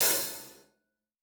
TC Live HiHat 06.wav